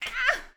SFX_Battle_Vesna_Defense_06.wav